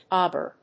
• : -ɒbə(ɹ)